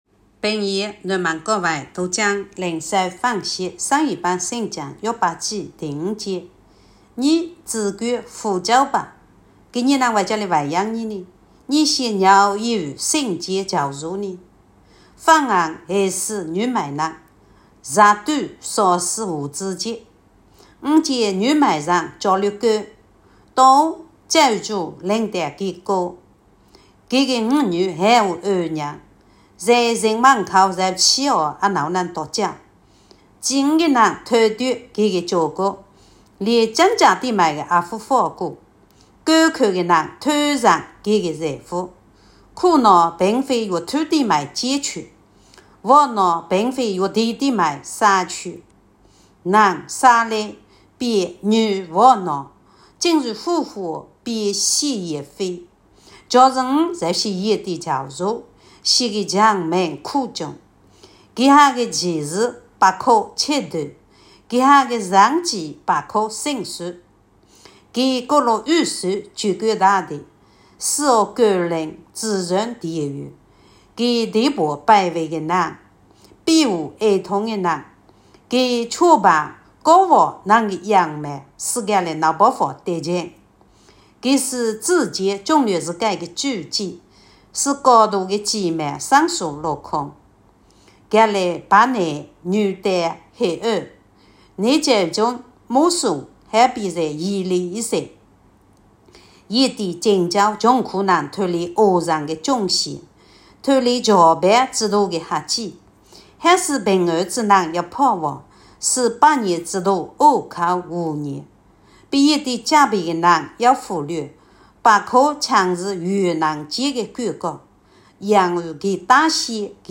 平阳话朗读——伯5